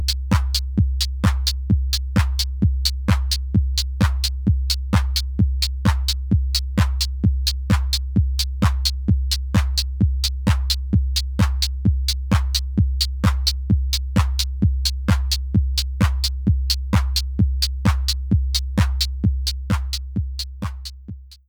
10ms:
You’ll notice that with claps, it’s relatively unimportant. With two kicks on top of each other, it becomes problematic.